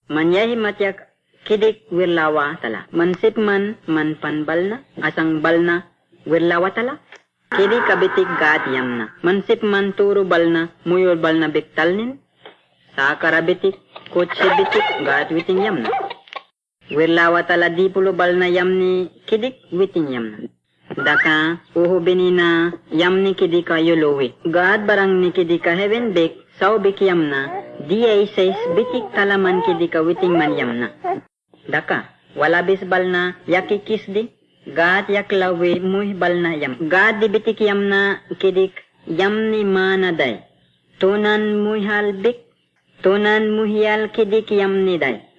9 March 2015 at 7:58 pm Note the singular barking phonation and cluck consonants at 0:13.